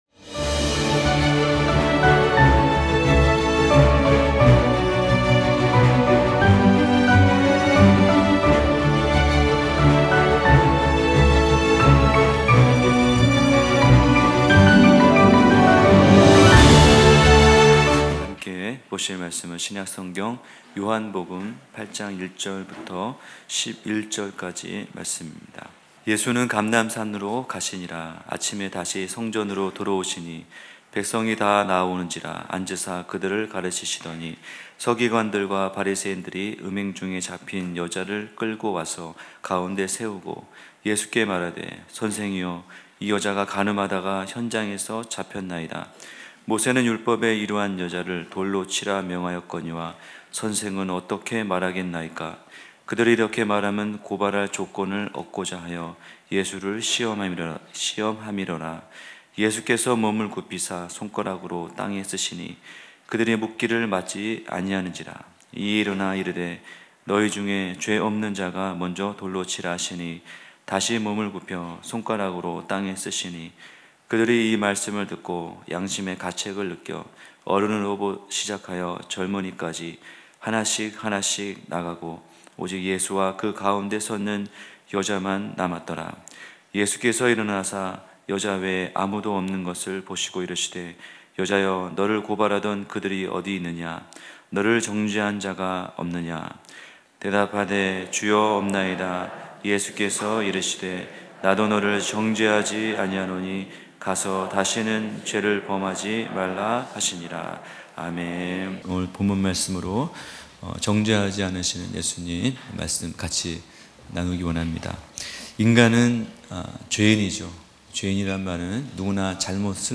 주일예배말씀